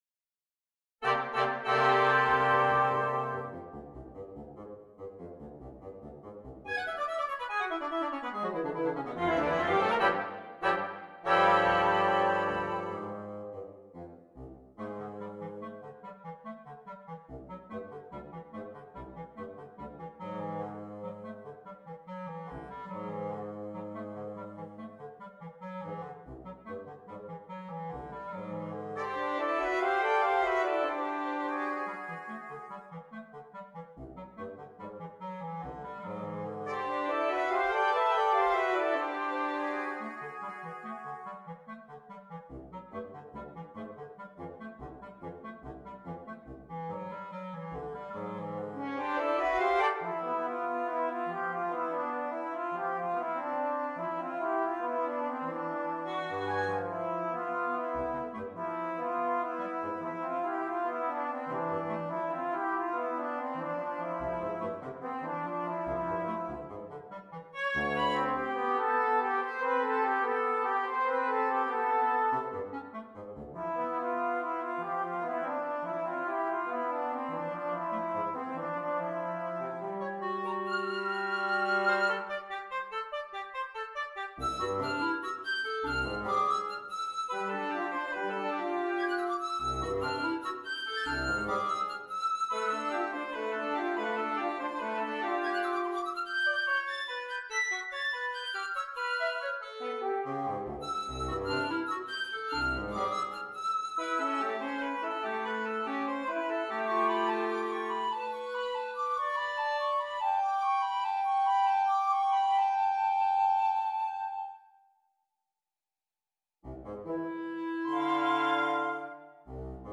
Flute,Oboe,Clarinet,Horn,Bassoon,Trumpet,Trombone